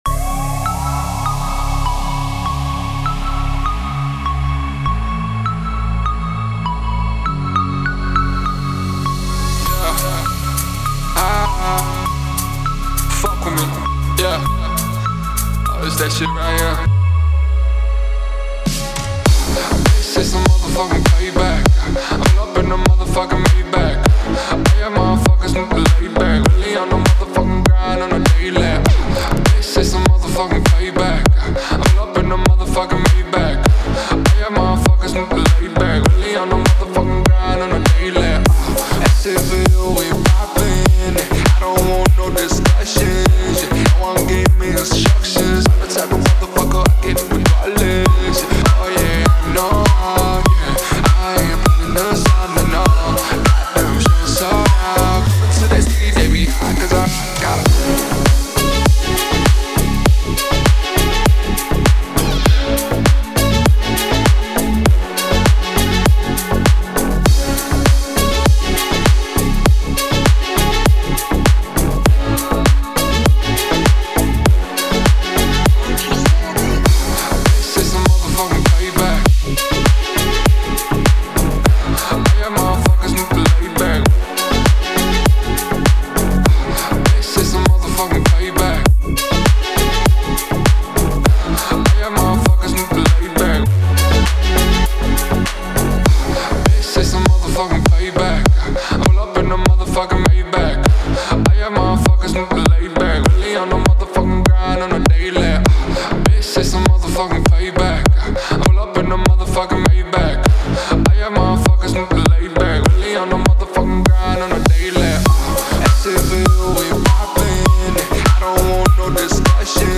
это яркий трек в жанре хип-хоп